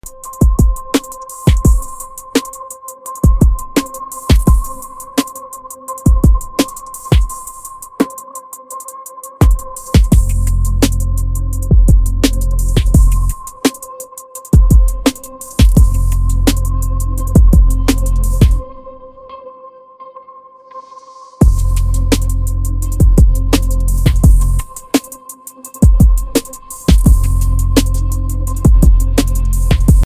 catálogo de beats